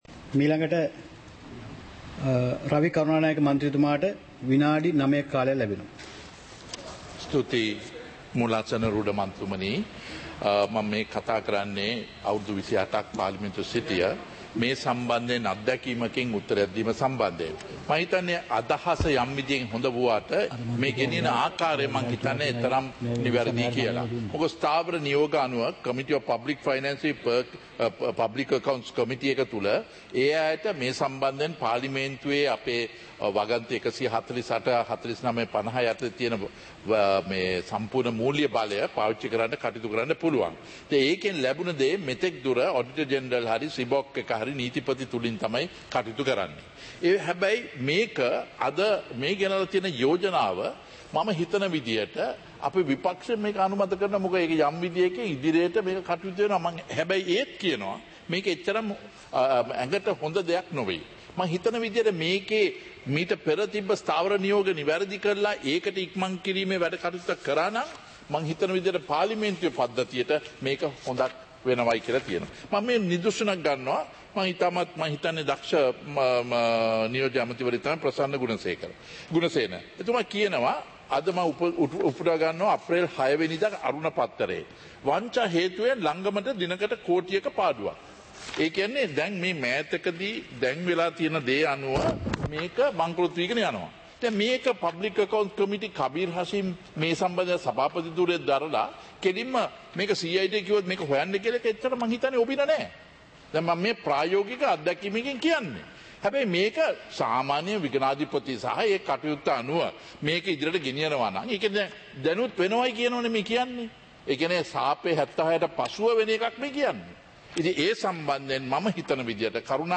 Proceedings of the House (2026-04-07)
Parliament Live - Recorded